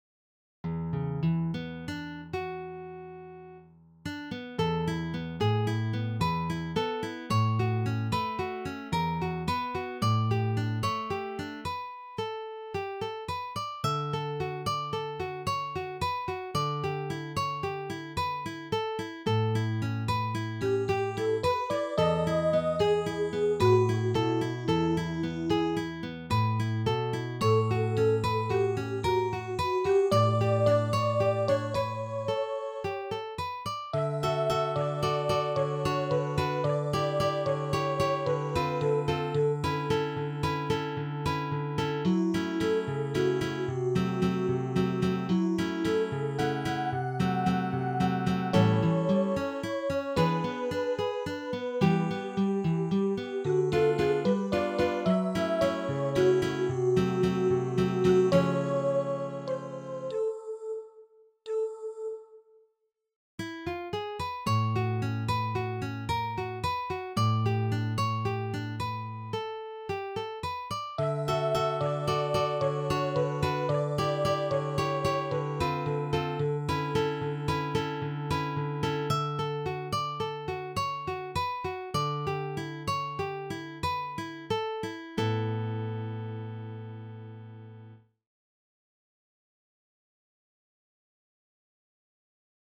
for Mezzo-soprano and Guitar
a song cycle for voice and guitar